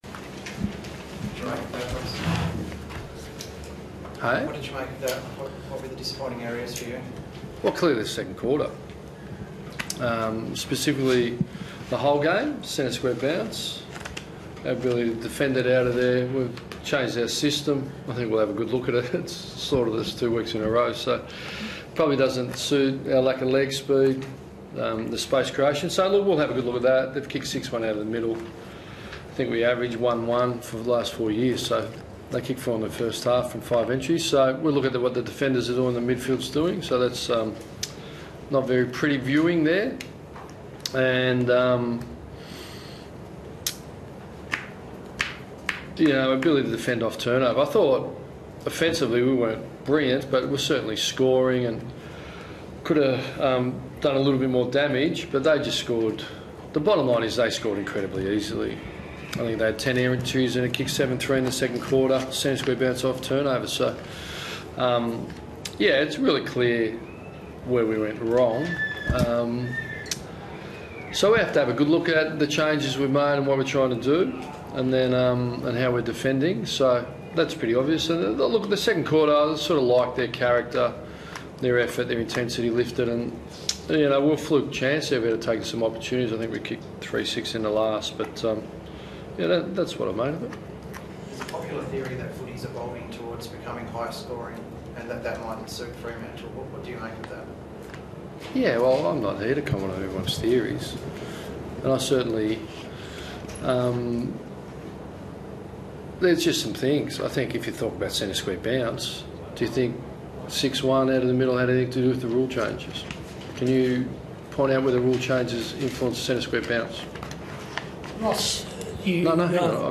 Ross Lyon spoke to the media following the loss against Gold Coast